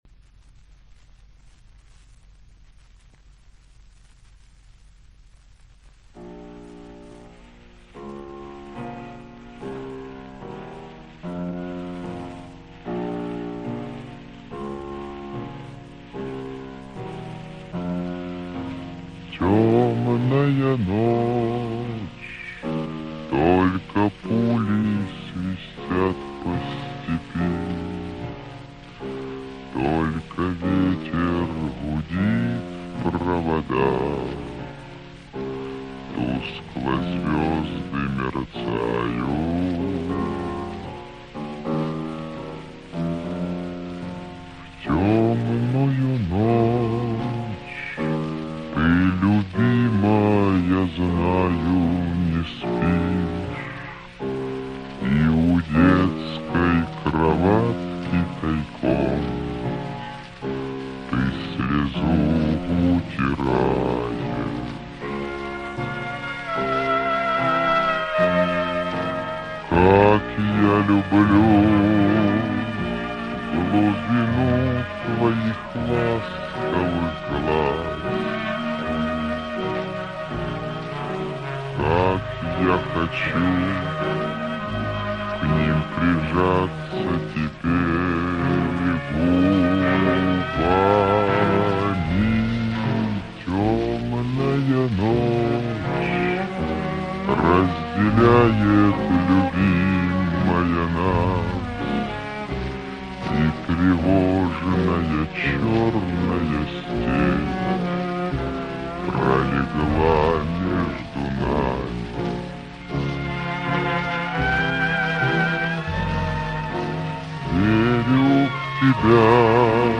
TikTok Ultra Super Slowed